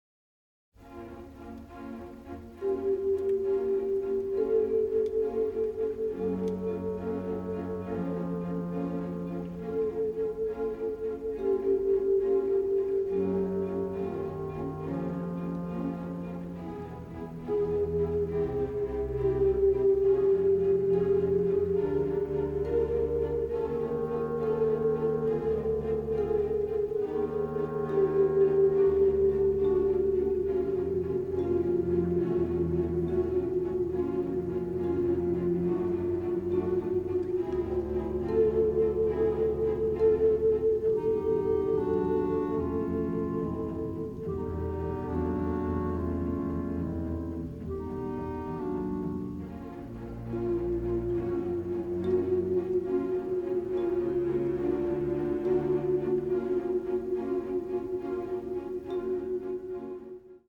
Original Calrec Soundfield - Stereo Microphone Mix